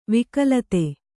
♪ vikalate